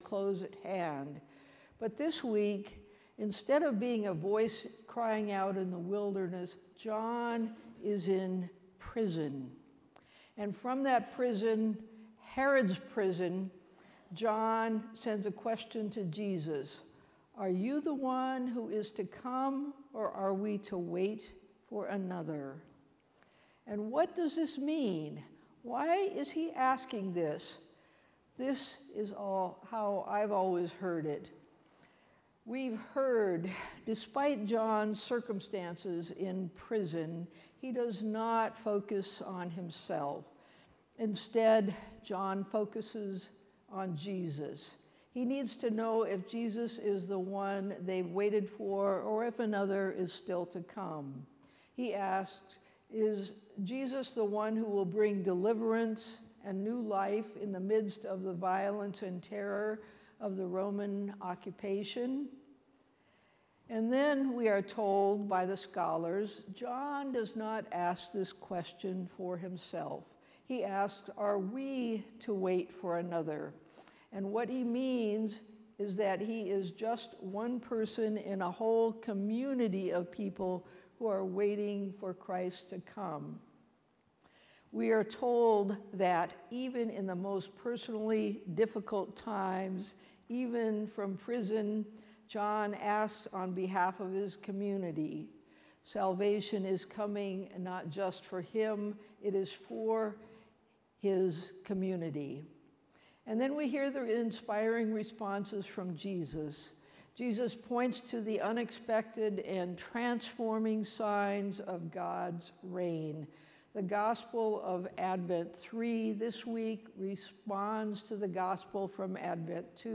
Sermon for December 14, 2025 – Saint Paul Lutheran Church